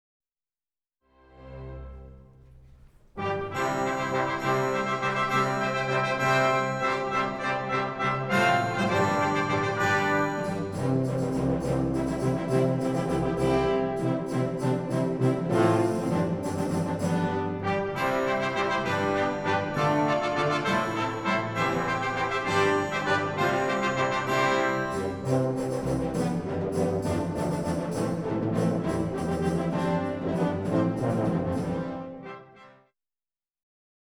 v. Postlude is another antiphonal brass work